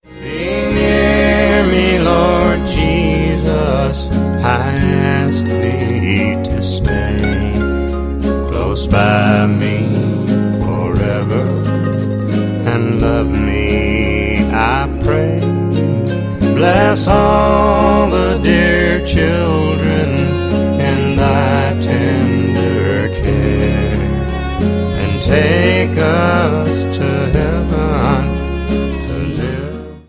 • Sachgebiet: Advent/Weihnachten Musik (Christmas)